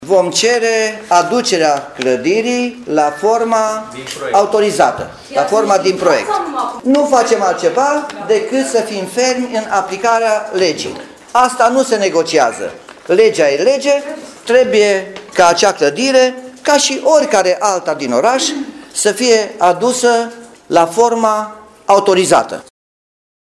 Primarul Nicolae Robu spune că municipalitatea va cere readucerea clădirii la forma iniţială.